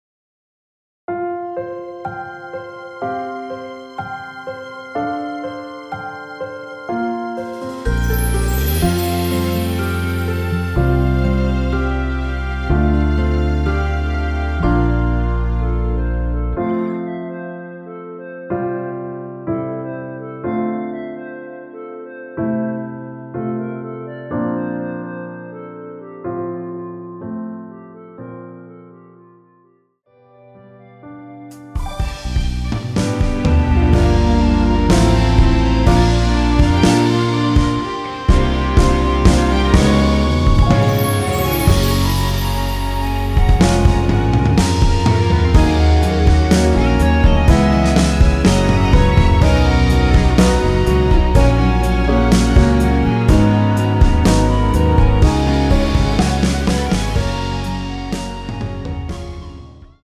원키 멜로디 포함된 MR입니다.
앞부분30초, 뒷부분30초씩 편집해서 올려 드리고 있습니다.